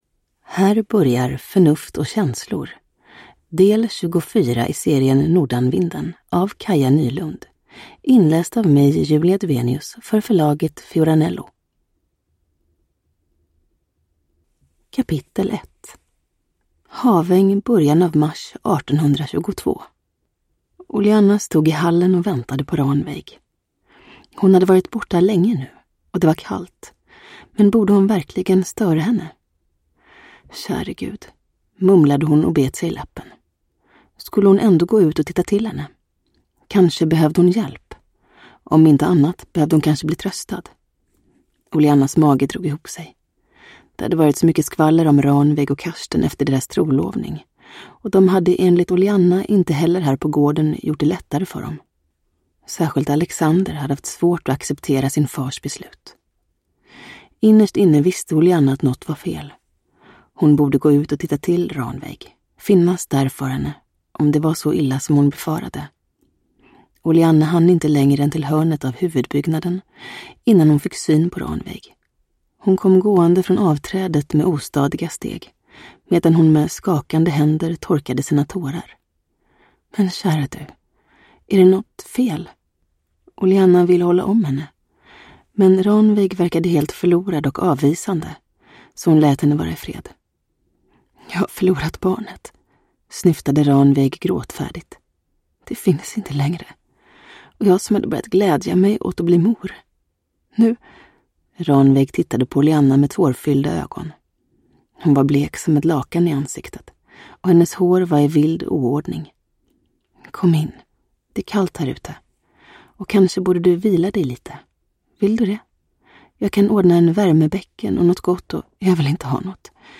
Uppläsare: Julia Dufvenius
Ljudbok